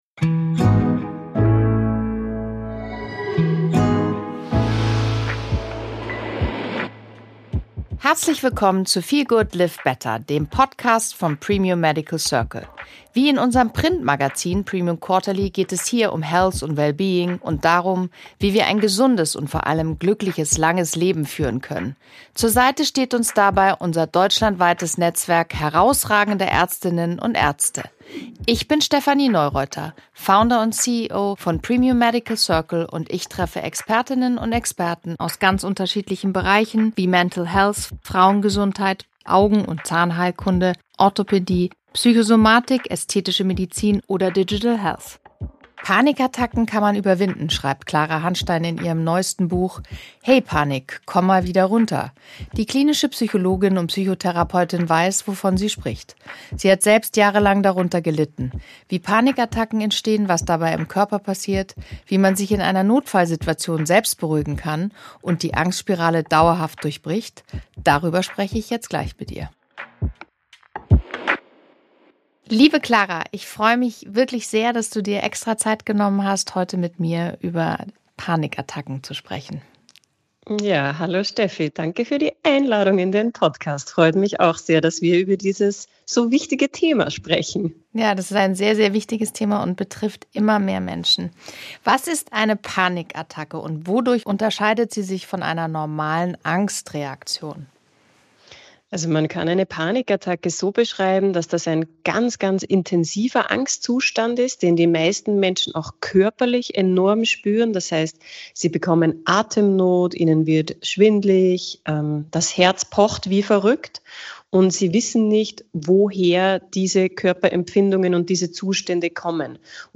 Klinische Psychologin, Psychotherapeutin und Bestsellerautorin